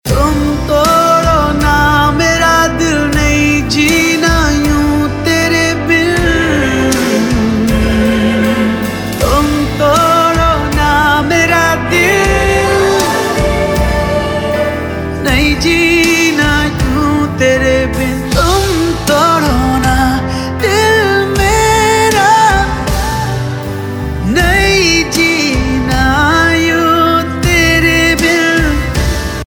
.mp3 Song Download Bollywood Mazafree
Ringtones